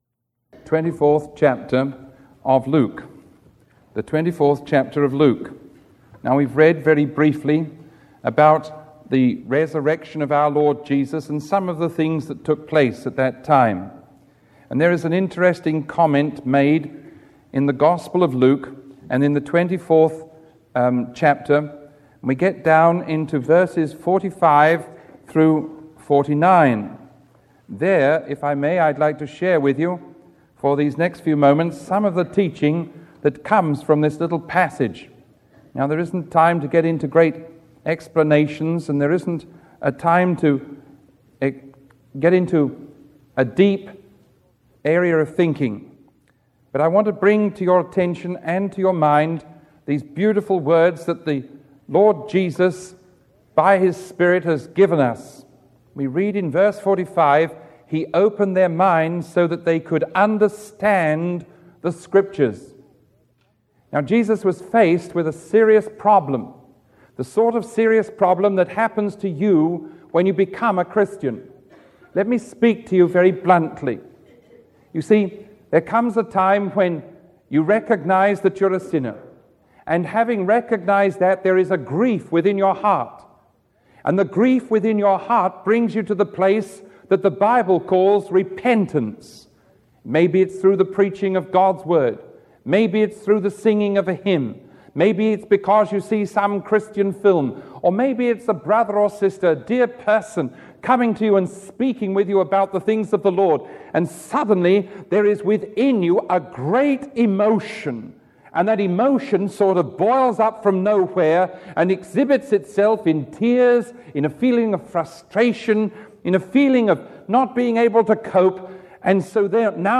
Sermon 0881A recorded on April 29